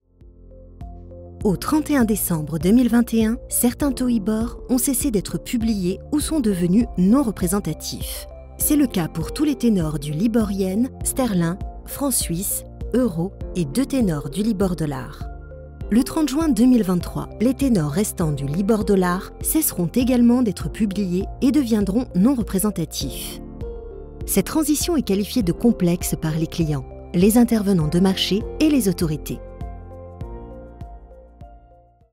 Corporate Videos
French voice over actress native from France, neutral accent.
My voice can be natural, sensual, dramatic, playful, friendly, institutional, warm and much more …
Mezzo-Soprano